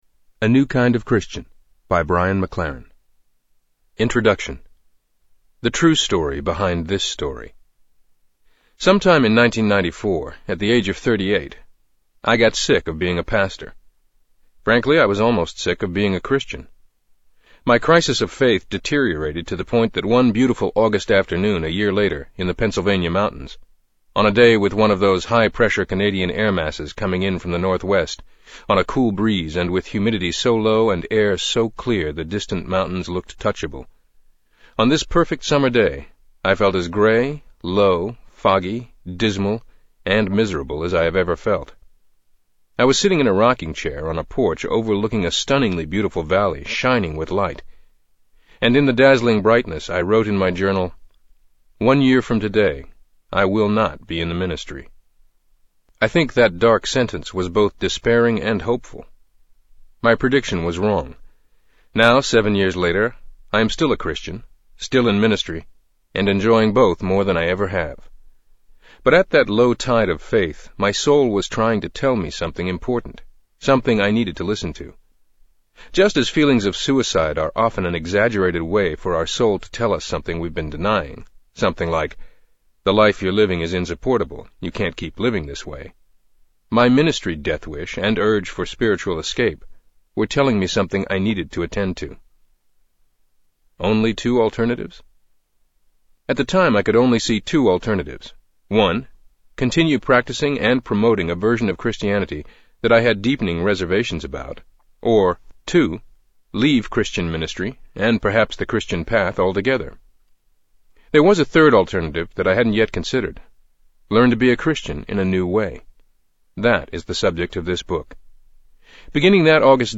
Tags: Media Writer Christian Christian audio books Audio books